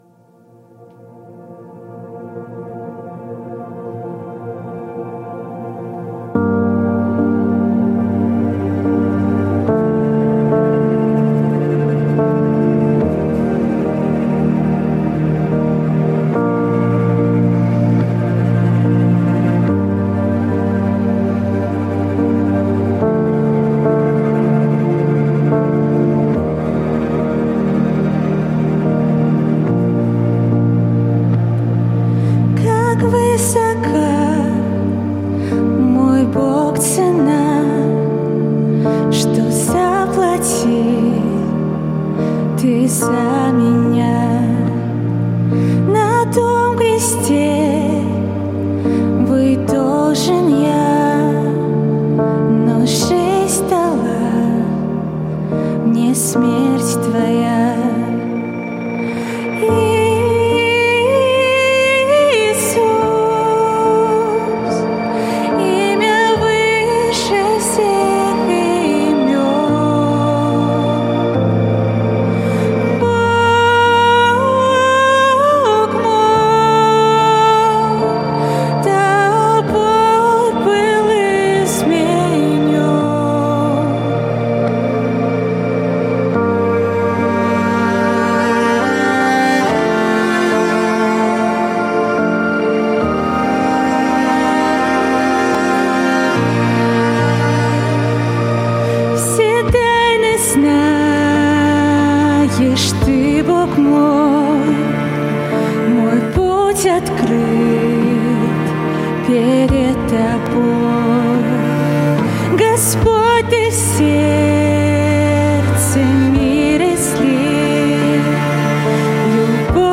Богослужение (ВС, утро) - 2 ноября 2025
Псалом Как высока, мой Бог, цена